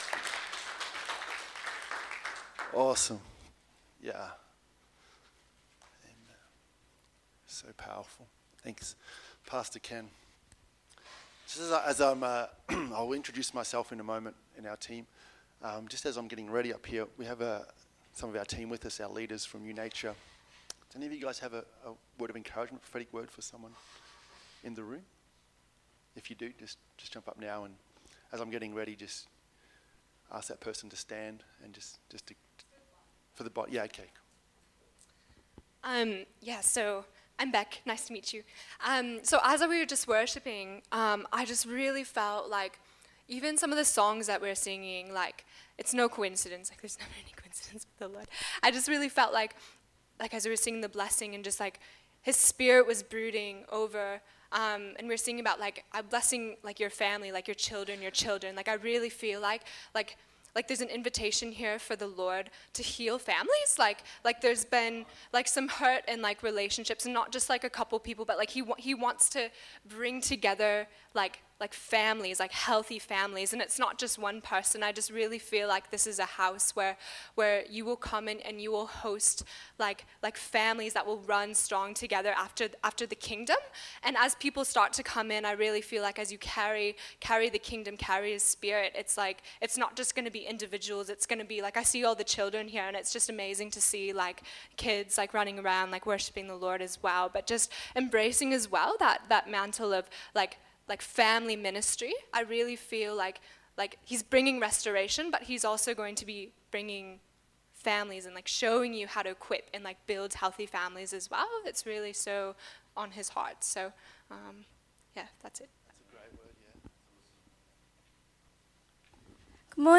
Sunday Message
Sunday-Service-28.2.21.mp3